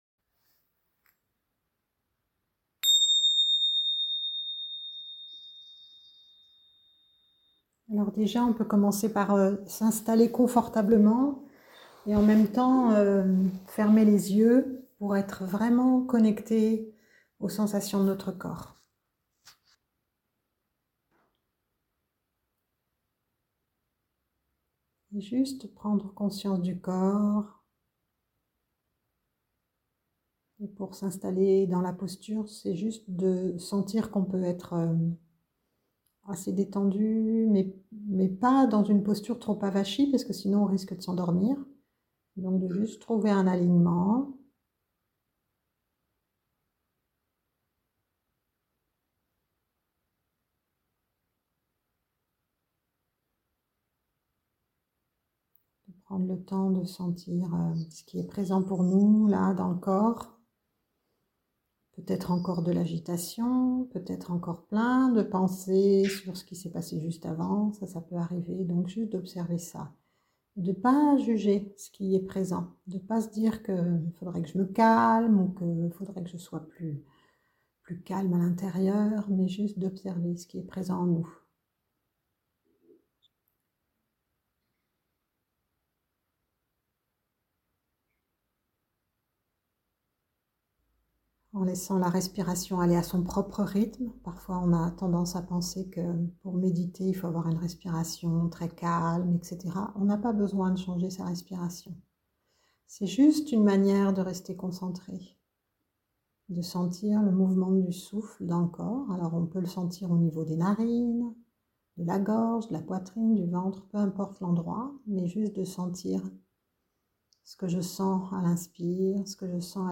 Découvrez des techniques simples et une méditation guidée de pleine conscience.
Meditation-pour-debutants.mp3